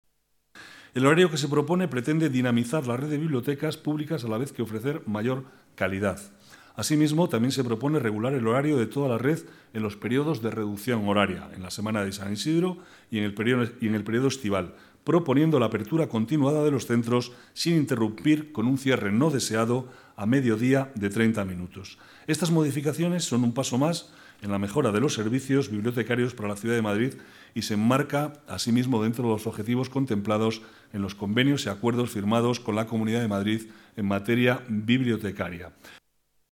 Nueva ventana:Declaraciones vicealcalde, Manuel Cobo: ampliación horario bibliotecas